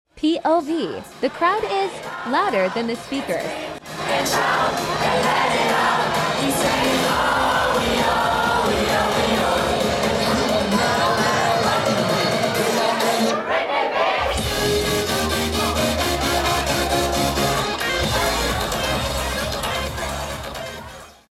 Crowd On Fire 🔥 🧯🧯🧯 Sound Effects Free Download
Crowd On Fire 🔥 🧯🧯🧯 Sound Effects Free Download.